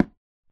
wood2.ogg